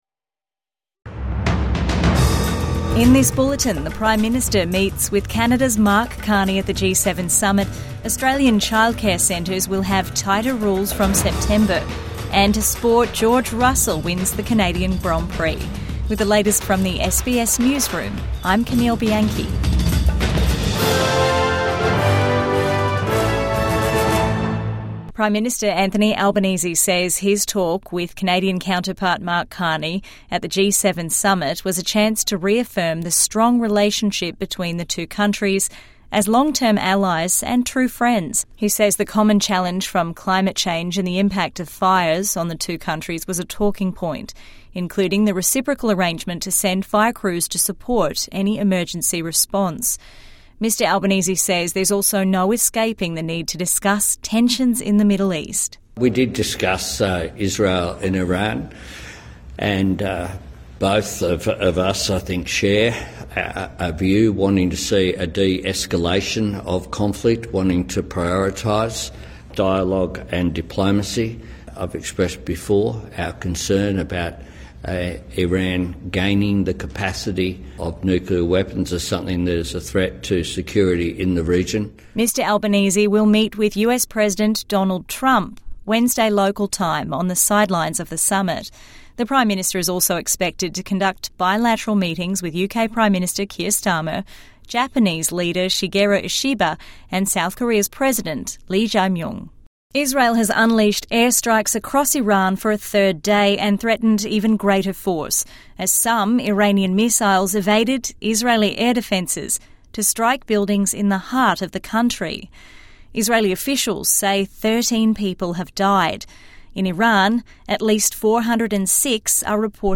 Midday News Bulletin